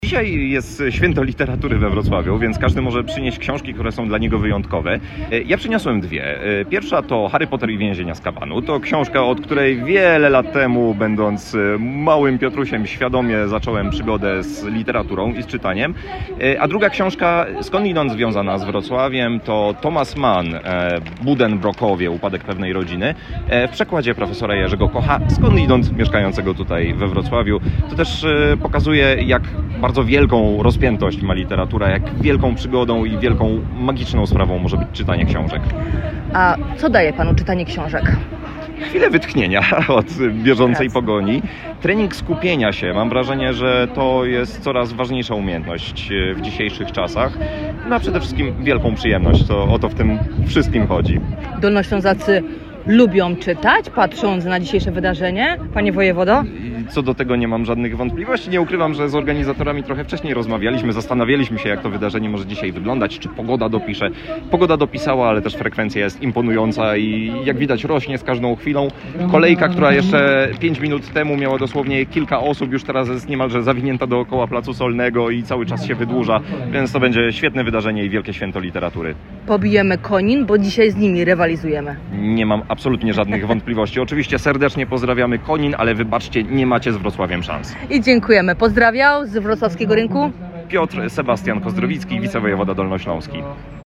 Swoimi ulubionymi książkami podzielił się z nami także wicewojewoda Piotr Kozdrowicki.